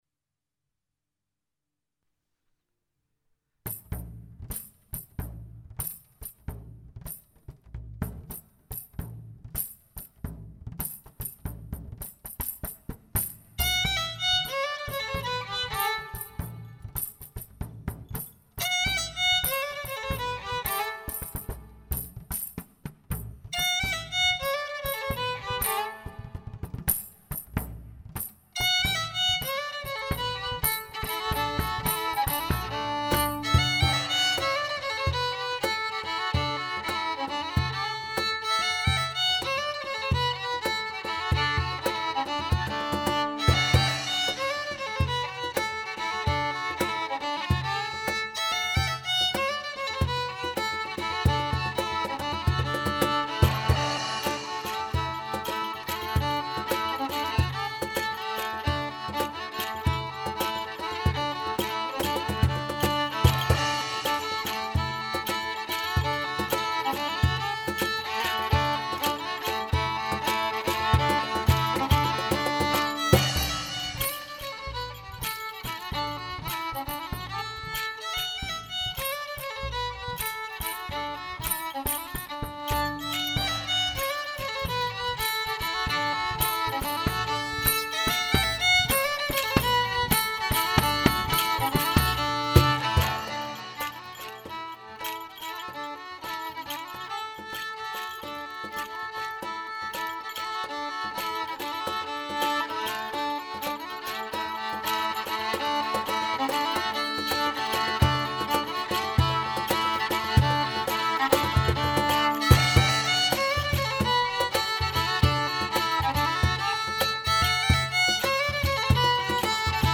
är en folkmusikduo som bildades i Arvika under hösten 2012.
sättningen fiol och slagverk.
• Svensk Folkmusik